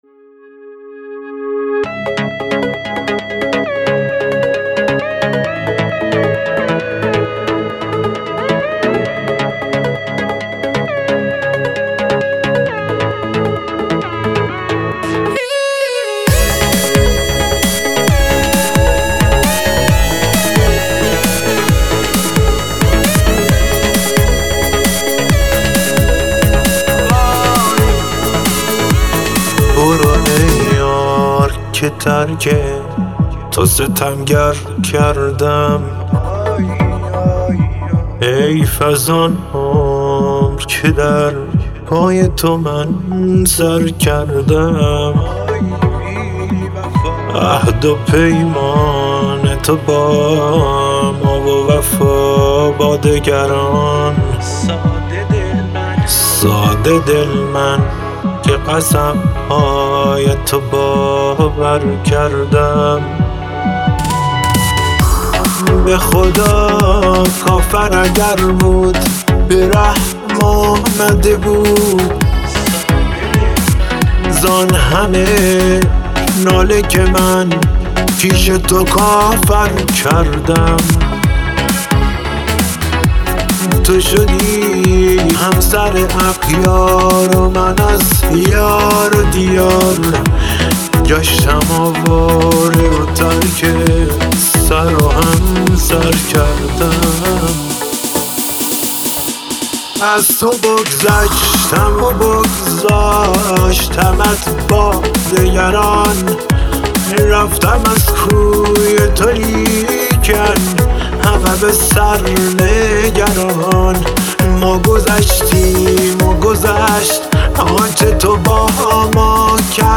آهنگ سوزناک و دلنشین